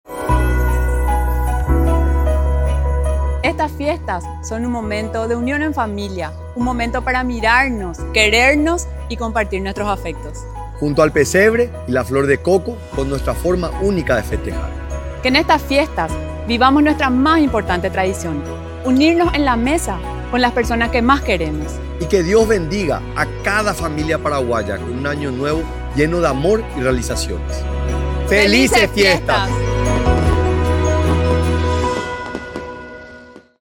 El presidente de la República, Santiago Peña y la primera dama de la Nación, Leticia Ocampos, expresan sus buenos deseos y las felicitaciones a todos los paraguayos por la Navidad.